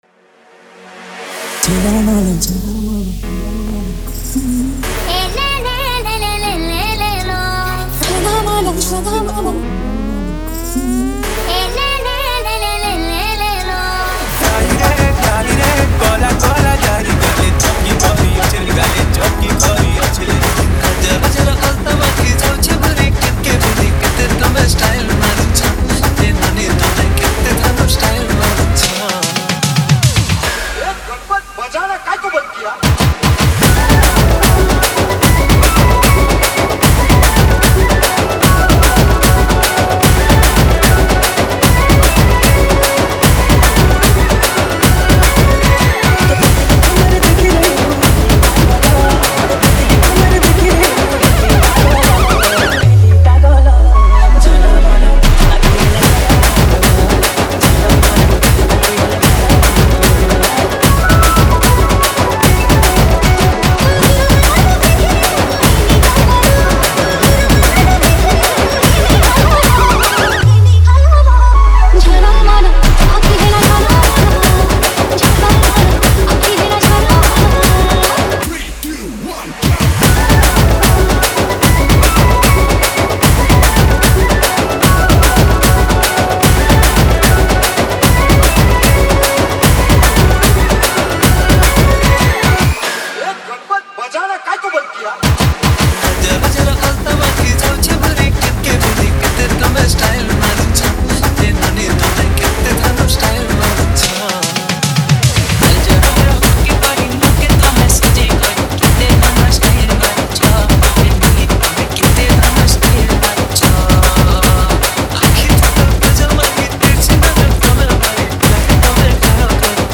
Ganesh Puja Special Dj 2023 Songs Download